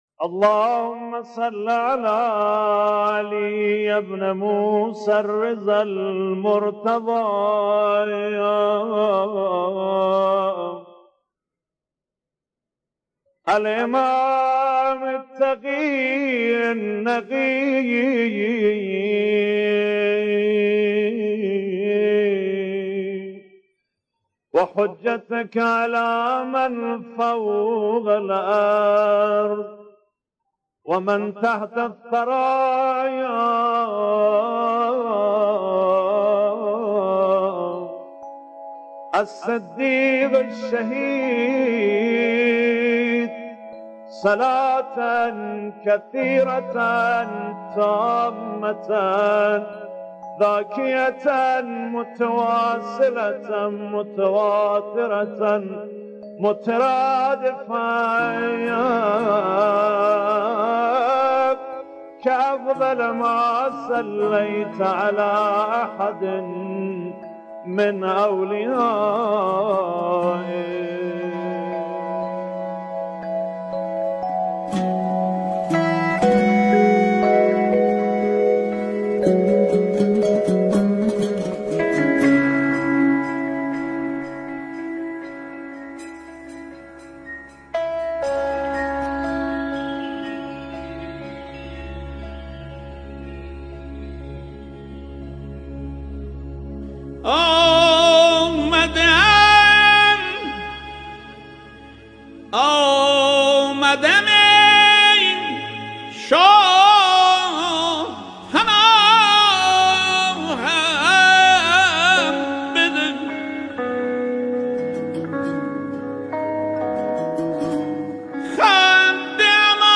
صدایی از کنار ضریح مطهر امام رضا